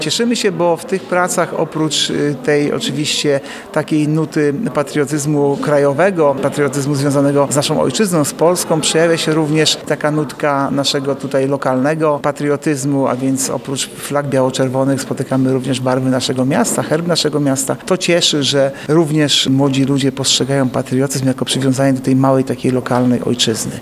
– Patriotyzm w dzisiejszych czasach ma różne oblicza, a uczestnicy nie zapomnieli w swoich pracach o najbliższej im ojczyźnie – mówi Artur Urbański, zastępca prezydenta miasta Ełku, jeden z członków komisji konkursowej.